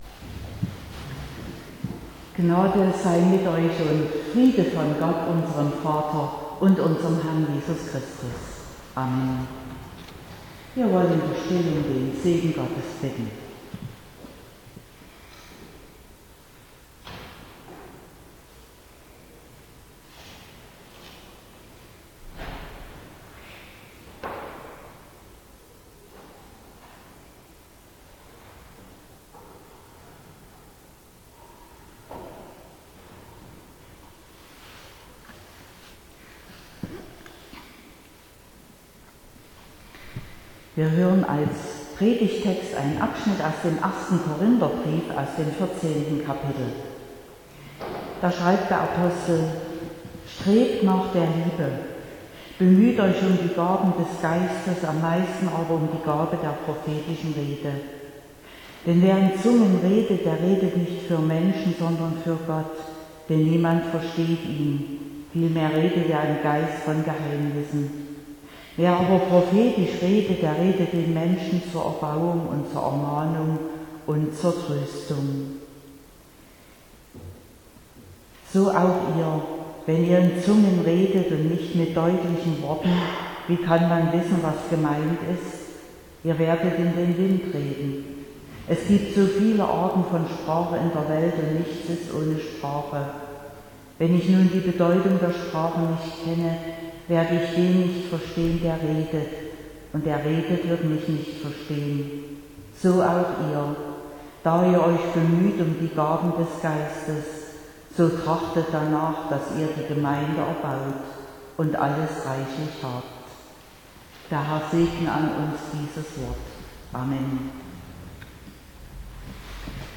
13.06.2021 – Gottesdienst
Predigt und Aufzeichnungen